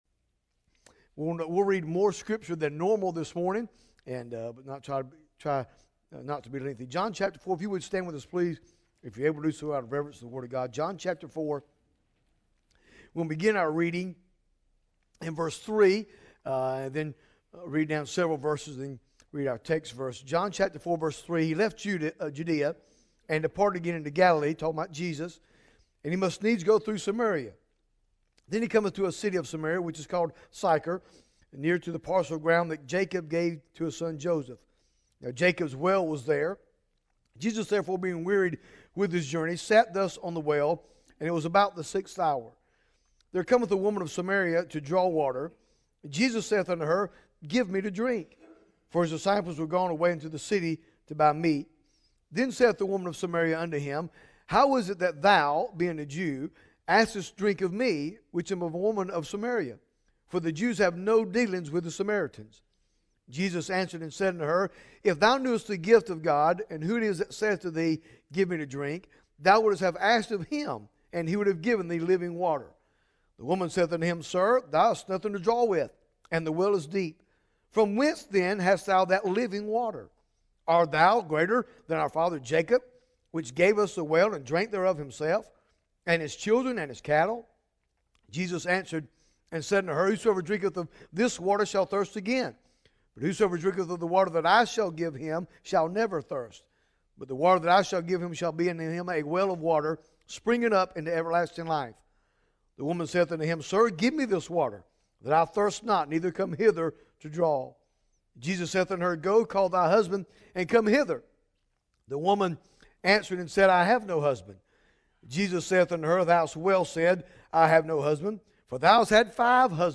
Bible Text: John 4 | Preacher